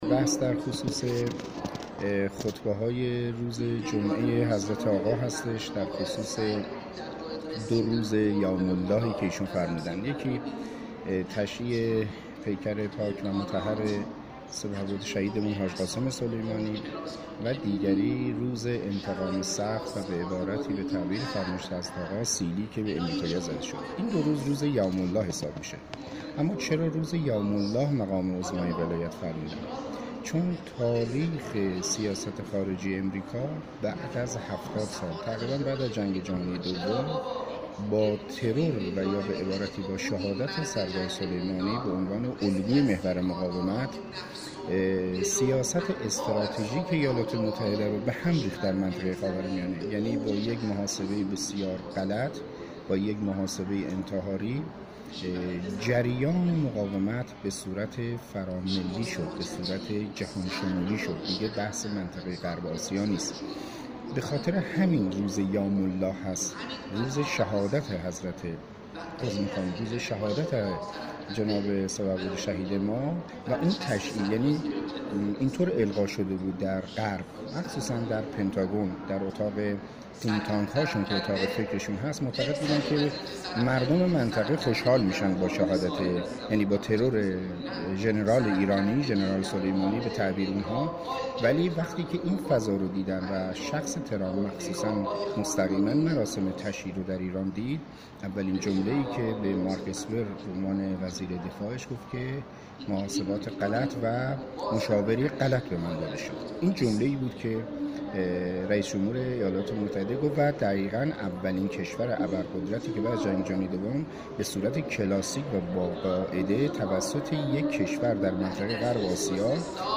امروز در همایش طلیعه حضور مدارس علمیه خواهران استان تهران که در مجموعه فرهنگی شهدای هفتم تیر برگزار شد